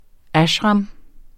Udtale [ ˈaɕʁɑm ]